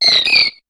Cri d'Azurill dans Pokémon HOME.